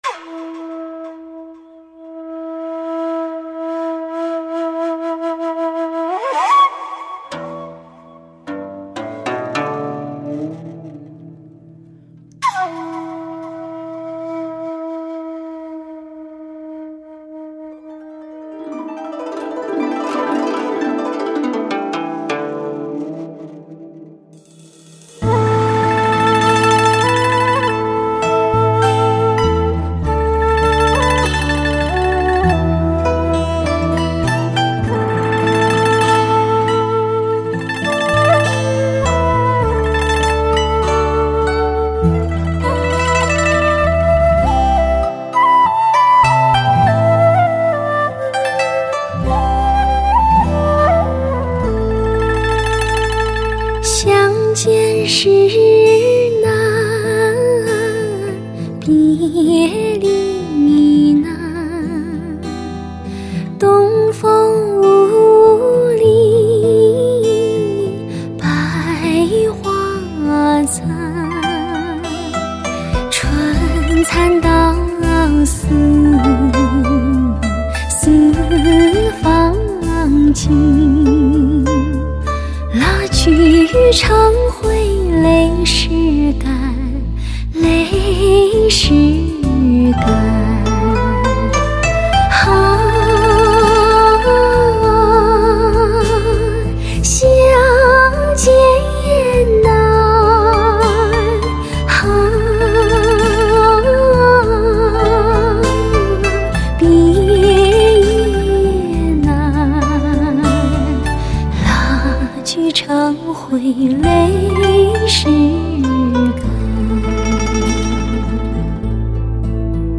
发烧人声
古诗新绎 ，古朴典雅，极富文化艺术内涵，
深情满怀的演唱，低吟浅唱中感受集散依依的情感眷恋。